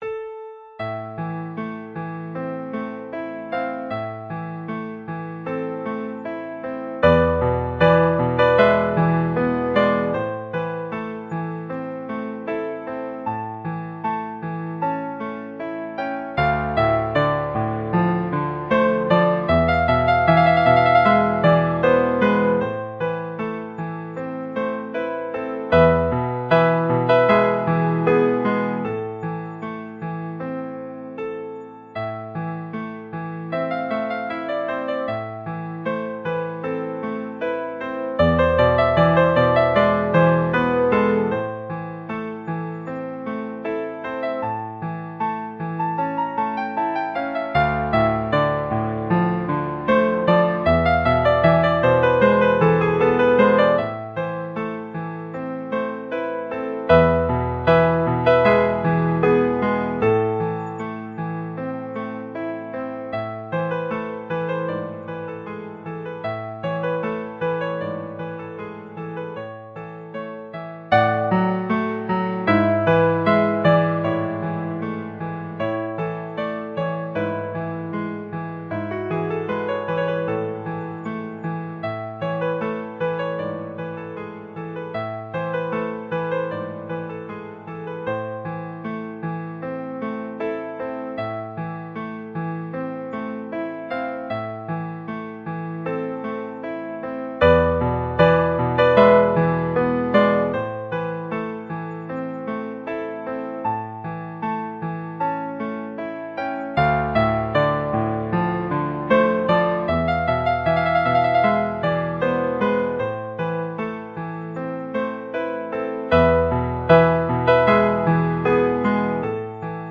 Untitled piano sonata.mp3
Untitled_piano_sonata.mp3